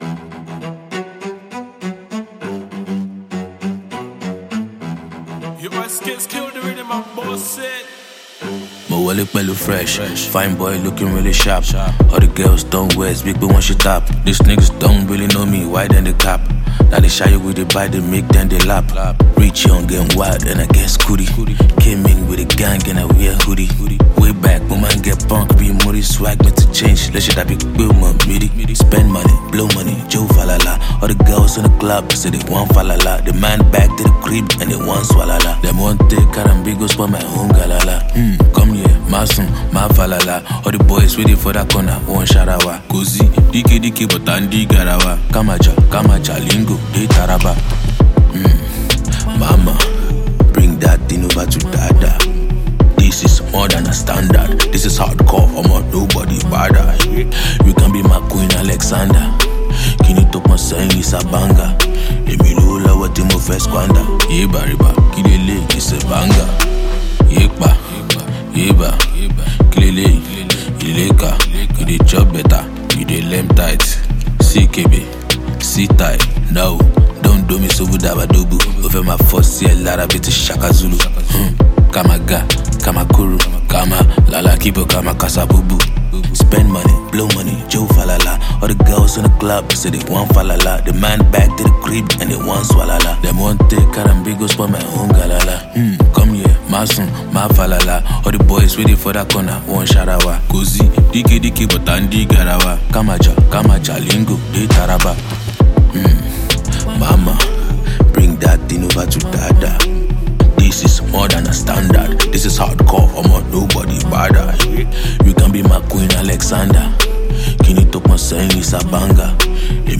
The album guarantees exceptional vocal delivery.